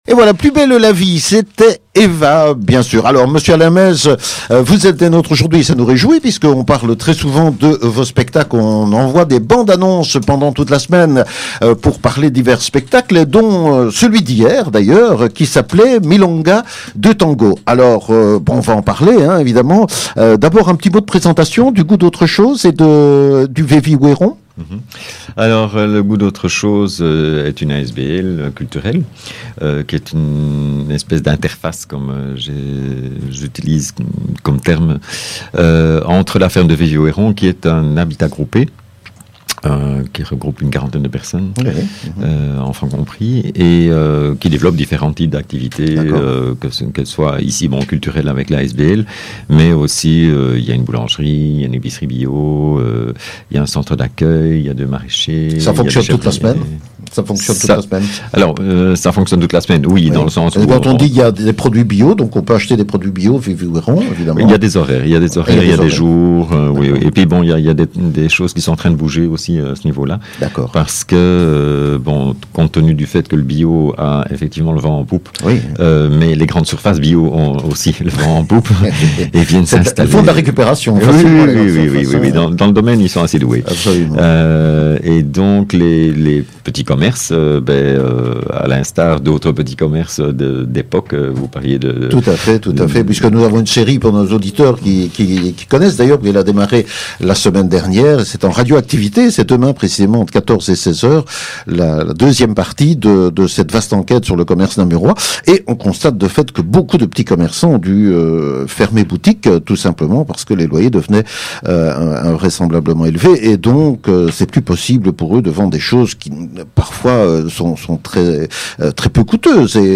Farniente: Programme du Goût d’autre chose à Wépion – interview